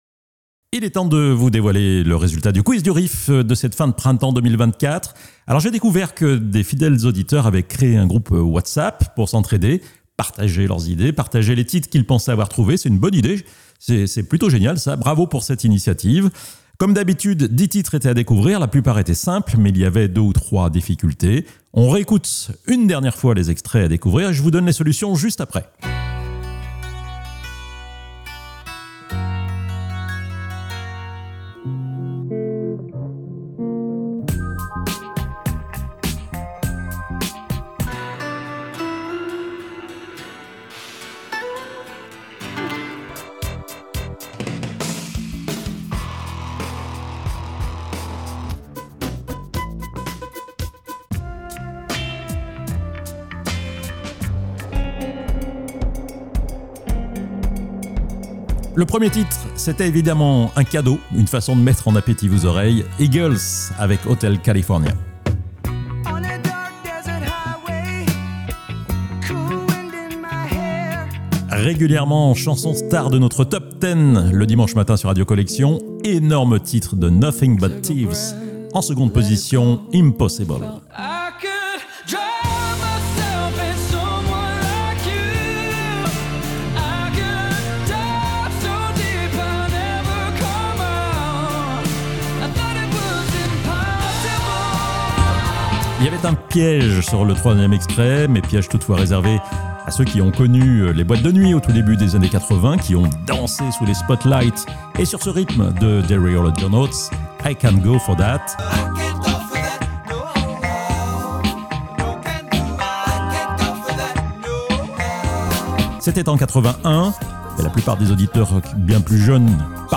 Ce podcast est l'enregistrement des solutions données à l'antenne le dimanche 21 Juillet.